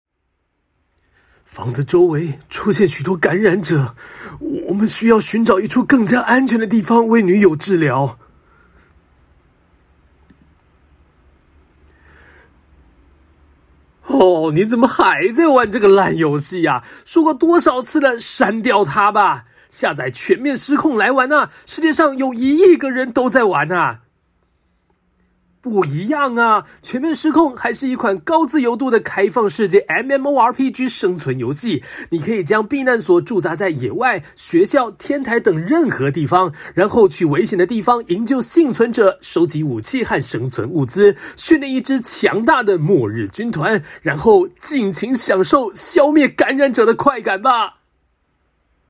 • 9台湾男声3号
广告对白